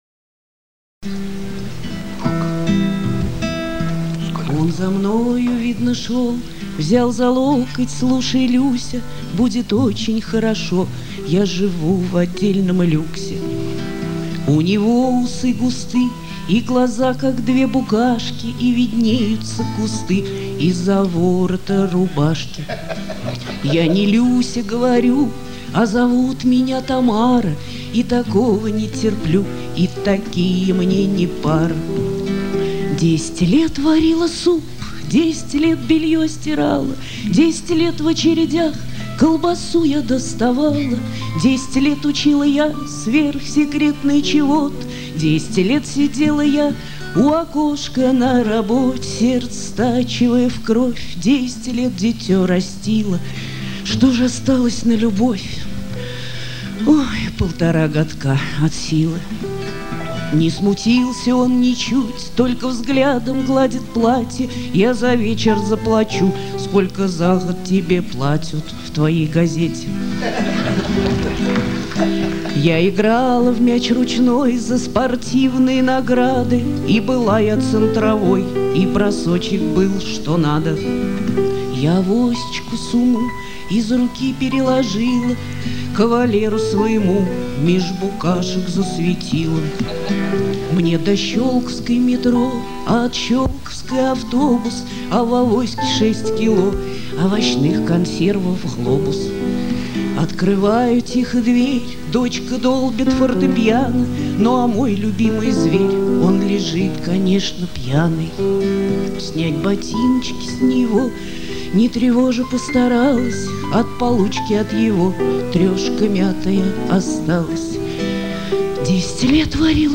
Фрагмент записи с телепередачи